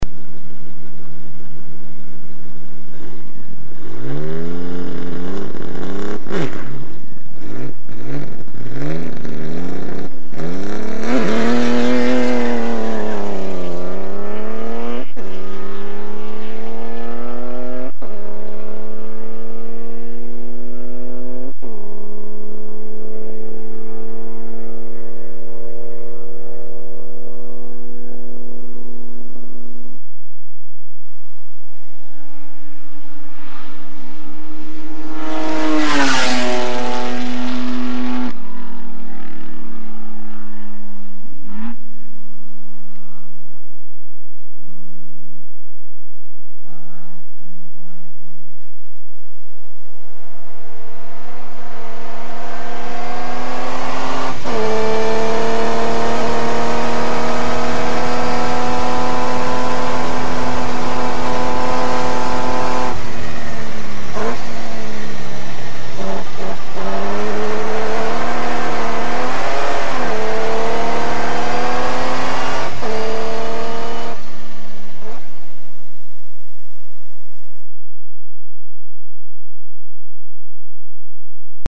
A jármű hangja általában hármas felosztásban hallható:
indulás, elhaladás, kocsiban ülve.